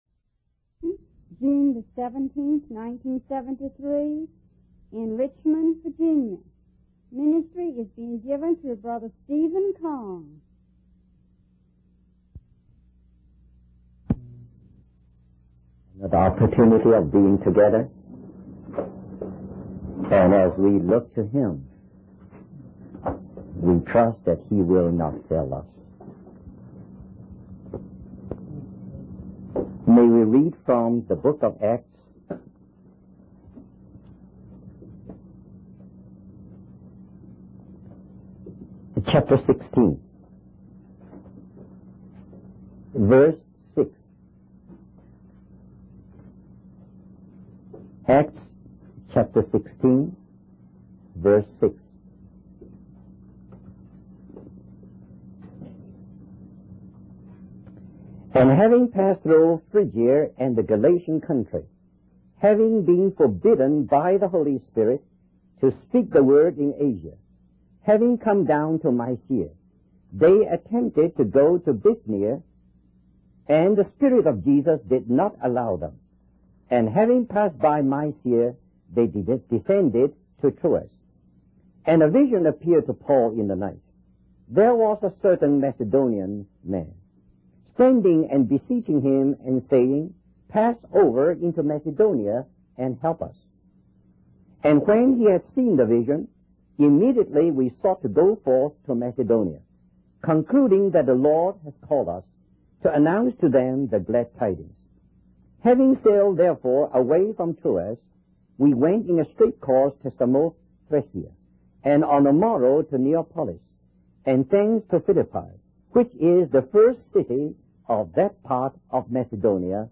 In this sermon, the speaker discusses the difficult situation that the apostle Paul found himself in.